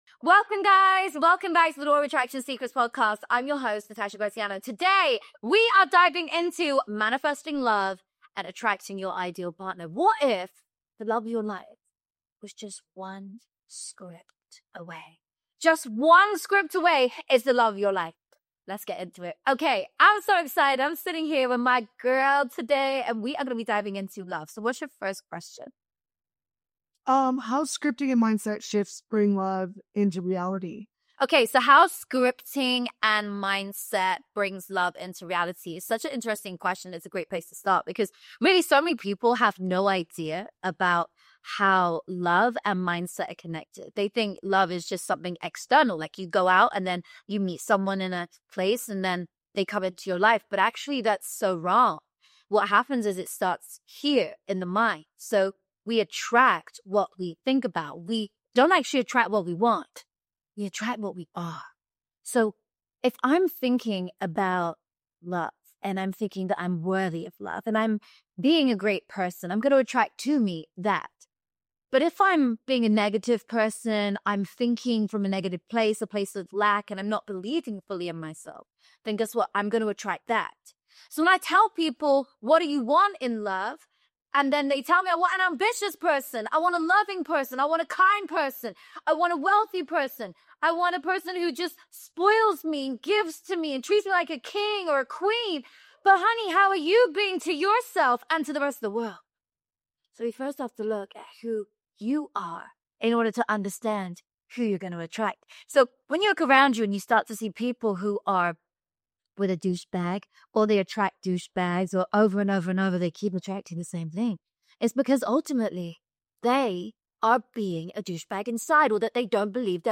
In this powerful solo episode